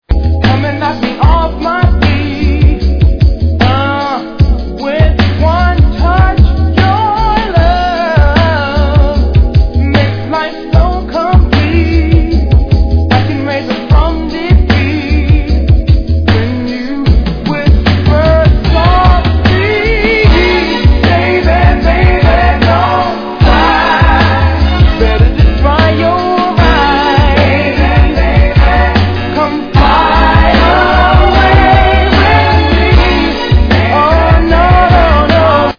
ゆったりとしたGrooveが人気のグラウンドビート
Tag       GROUND BEAT R&B UK